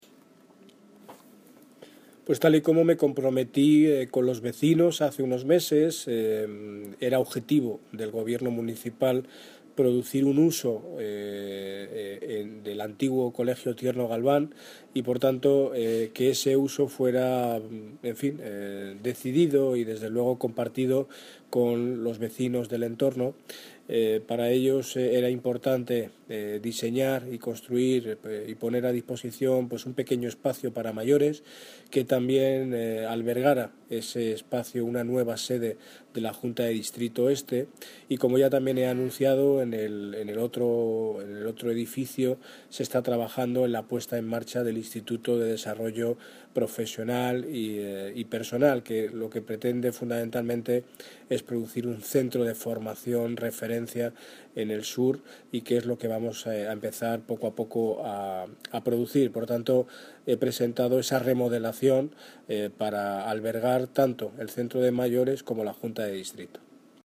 Audio - Daniel Ortiz (Alcalde de Móstoles) Tierno Galvan